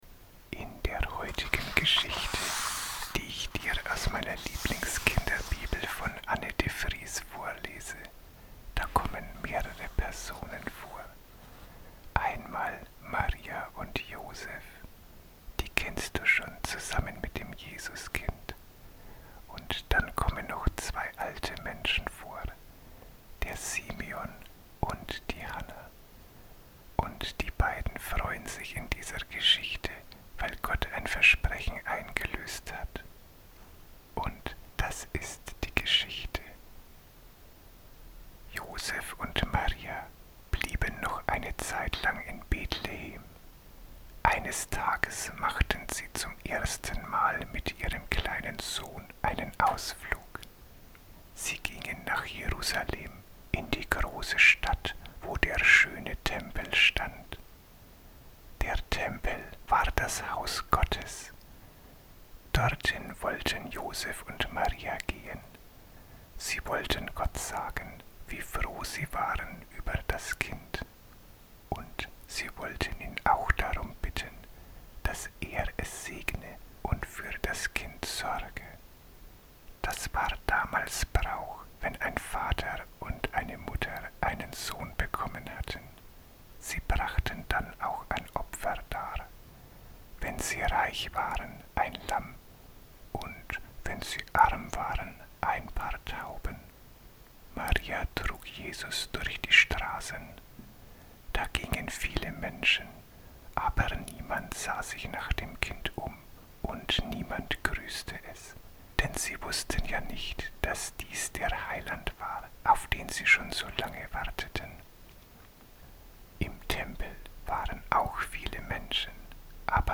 ist eine Form der Tiefenentspannung.
Beim Anhören der Geschichten empfehle ich die Verwendung eines Kopfhörers.